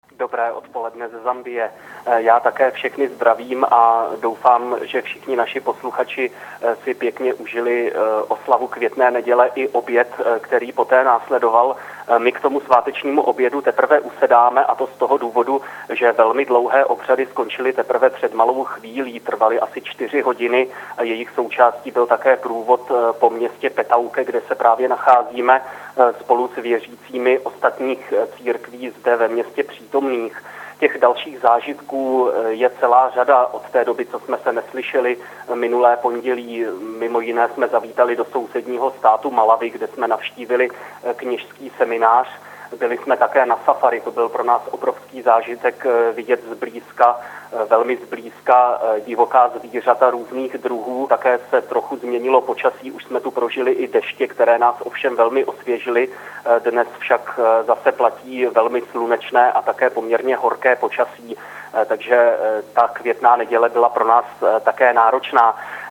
Posluchačům Proglasu se podruhé telefonicky přihlásili o Květné neděli před 14. hod. zdejšího i zambijského času.